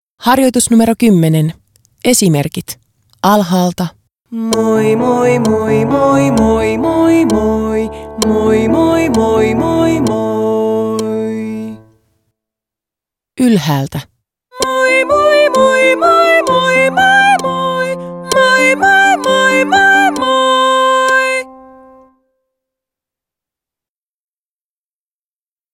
19. Esimerkki 10, buy-bay-boy-boo -ääniharjoitus
19-Esimerkki-10-buy-bay-boy-boo-ääniharjoitus.m4a